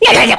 Lakrak-Vox_Dead_kr.wav